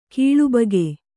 ♪ kīḷubage